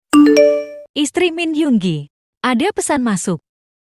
Genre: Nada dering Korea